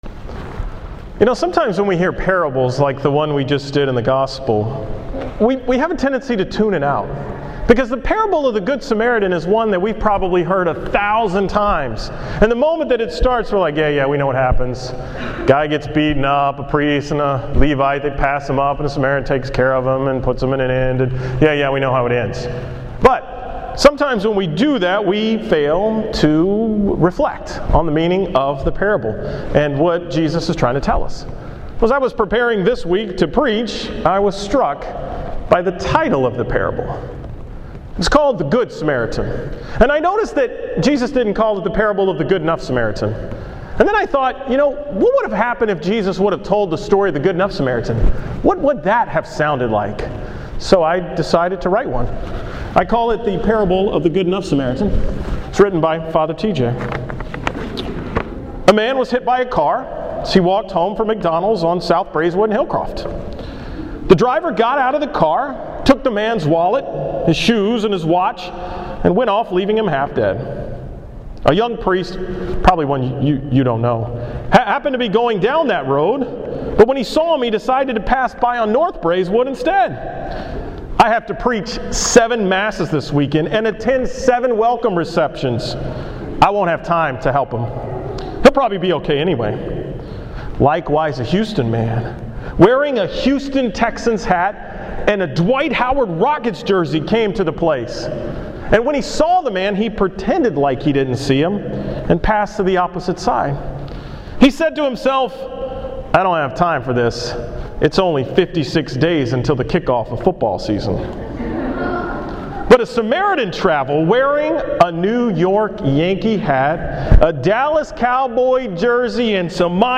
15th Sunday in Ordinary Time
Category: 2013 Homilies, Sports themed homilies